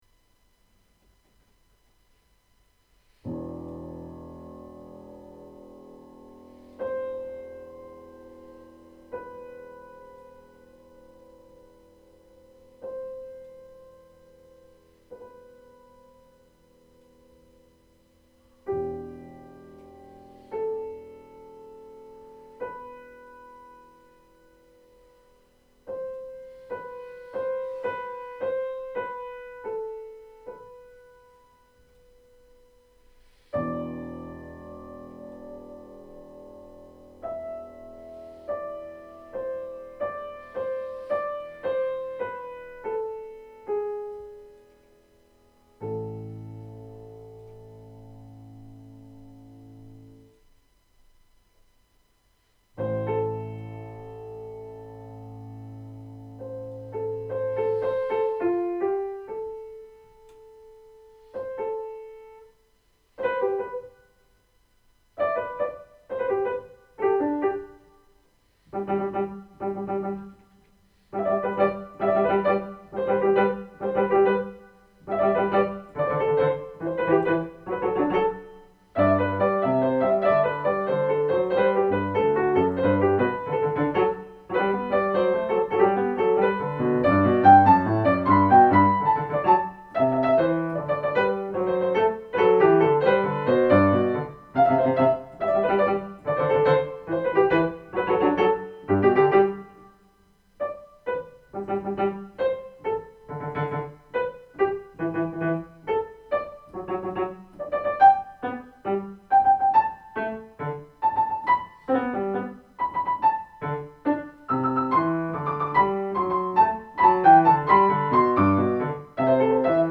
Deze ervaringen hebben me geïnspireerd om dit meerdelige werk te schrijven, het zijn sfeerbeelden in meditterane sfeer.
En dan ineens beginnen de vogels allemaal tegelijk met hun zang. We horen achtereenvolgens de koekoek, de wielewaal en de specht in een kort uitbundig feest van levenslust.